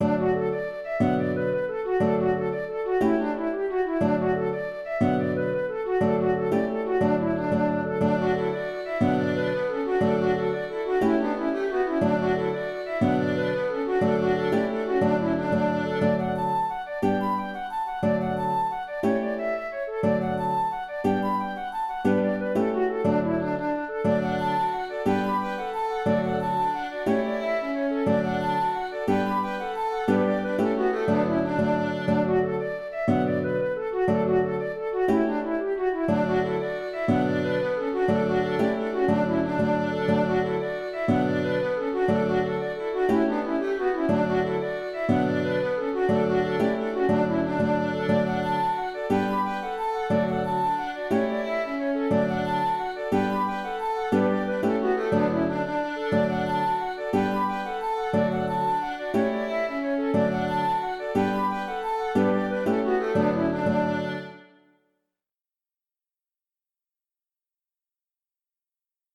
Jerry's Beaver Hat (Jig) - Musique irlandaise et écossaise
Auteur : Trad. Irlande.